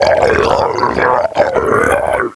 zo_idle01.wav